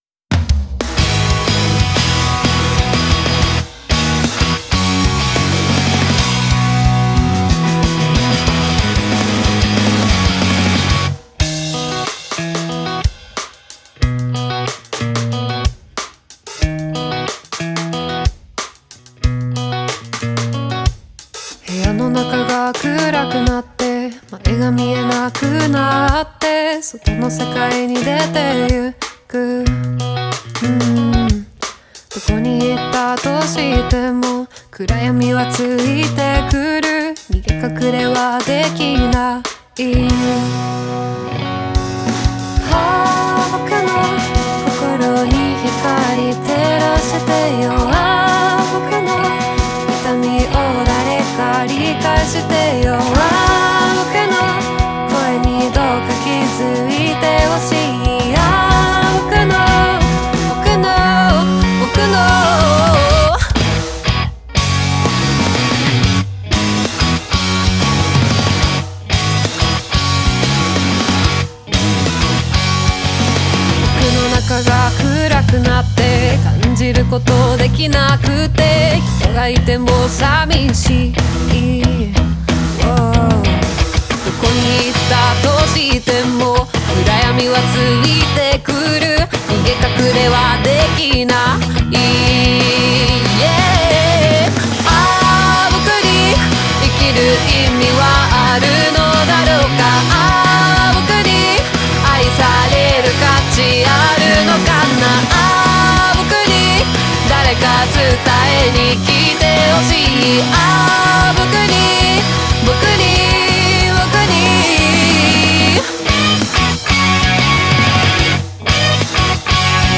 オリジナルKey：「B